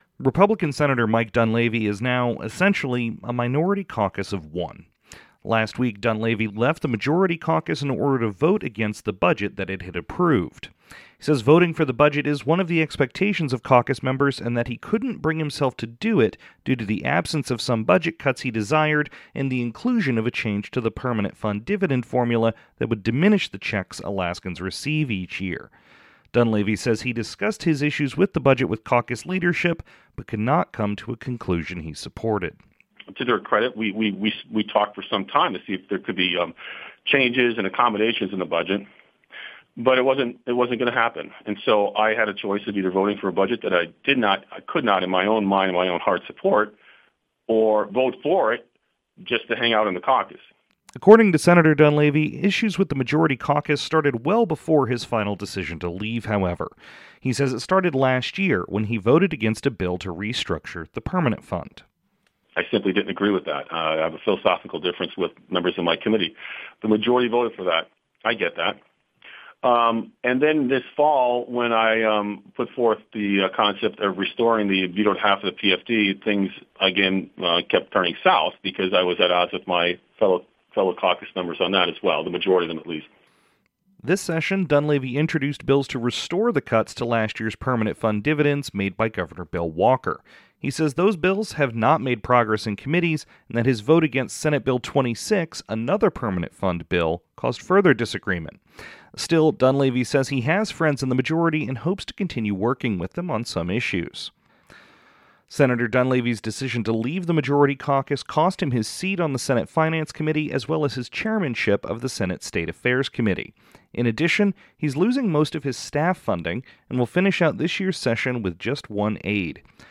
Dunleavy Speaks on Life in Juneau Outside of the Senate Majority | KTNA 88.9 FM